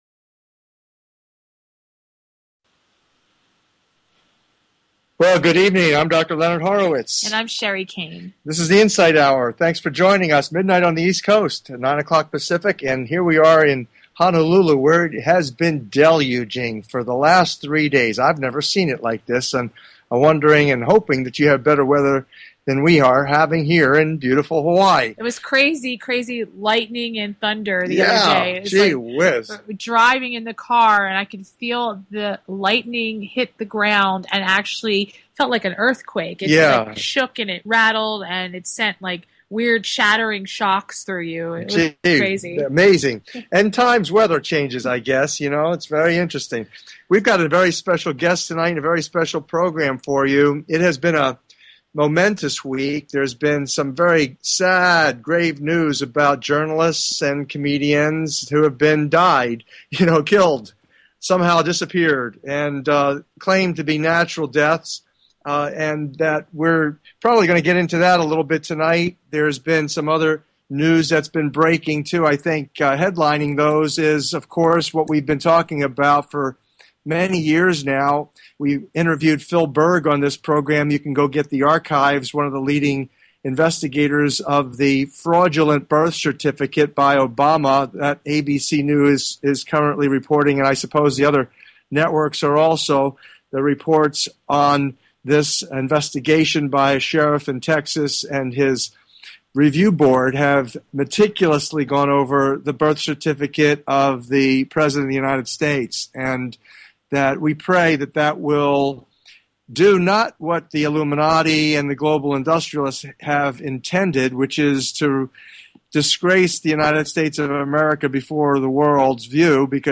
Talk Show Episode, Audio Podcast, The_Insight_Hour and Courtesy of BBS Radio on , show guests , about , categorized as